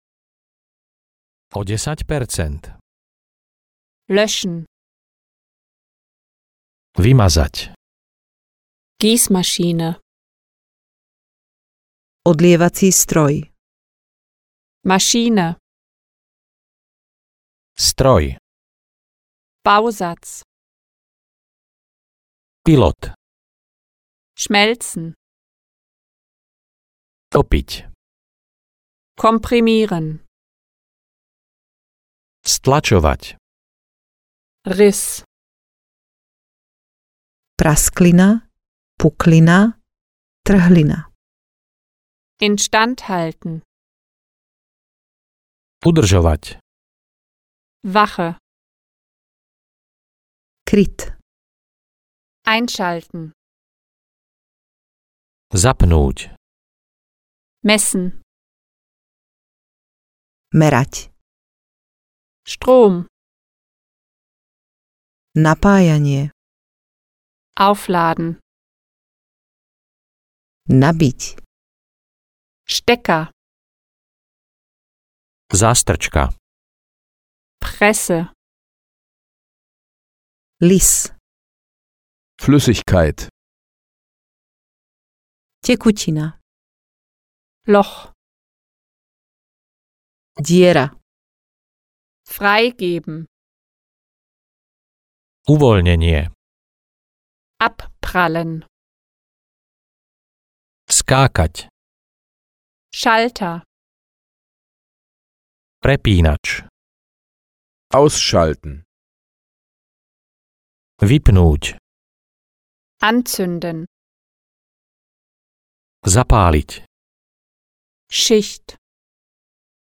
Technická nemčina A1-B1 audiokniha
Ukázka z knihy